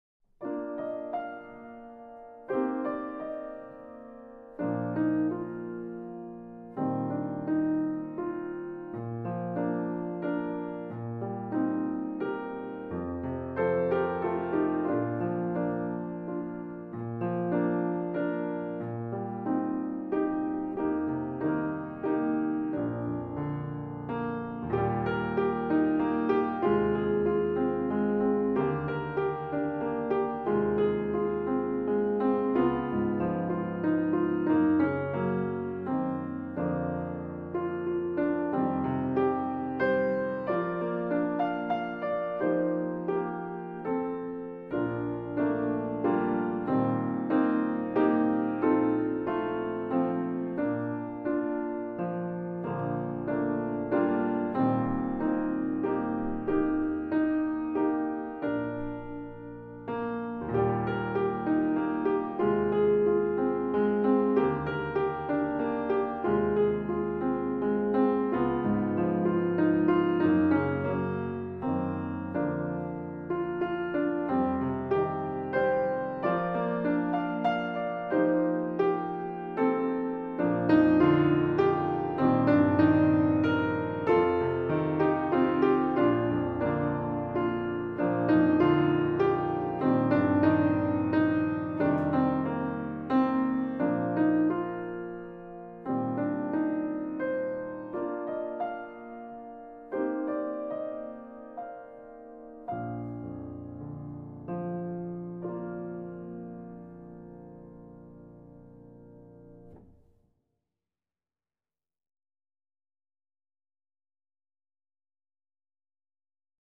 Accompaniment – Low Voice